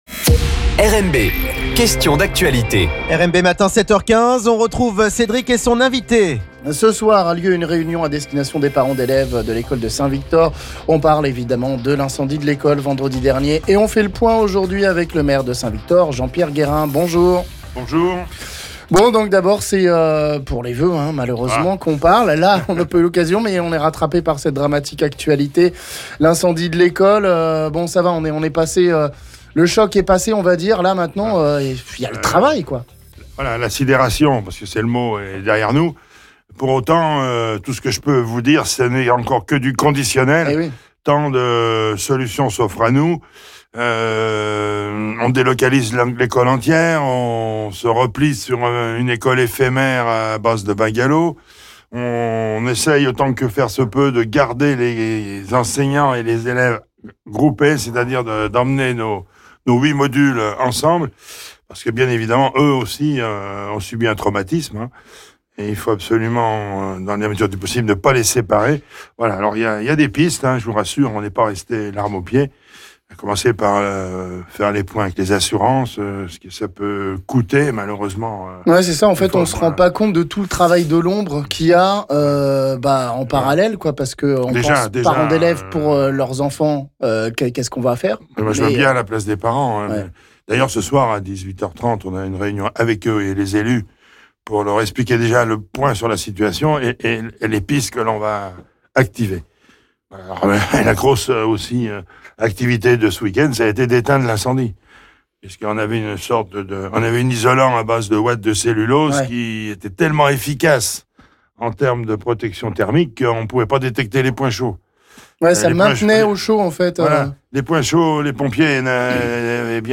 Après l'incendie de l'école de St-Victor vendredi dernier, le maire de St-Victor Jean-Pierre Guérin fait le point sur la situation, et il reste encore pas mal de travail afin de permettre un accueil des enfants dès lundi. Il y a une réunion d'information pour les parents d'élèves ce soir à 18h30 à la salle municipale...